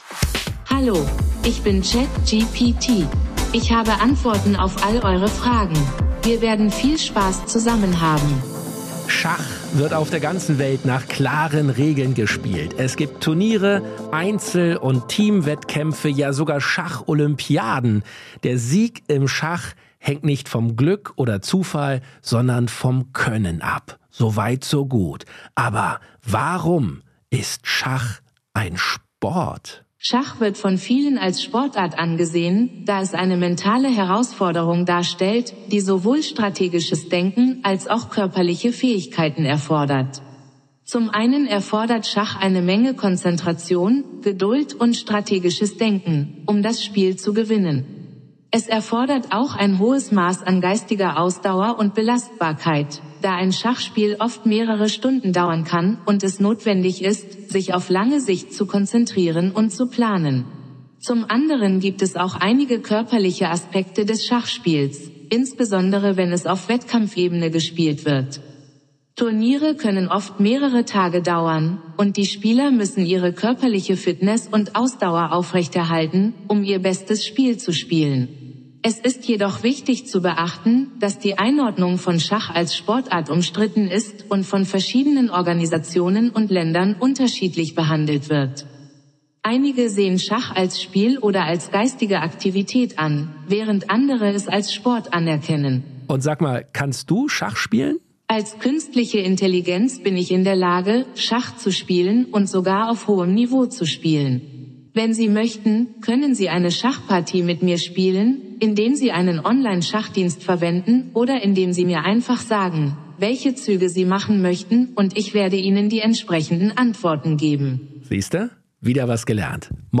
Künstlichen Intelligenz ChatGPT von OpenAI als Co-Host.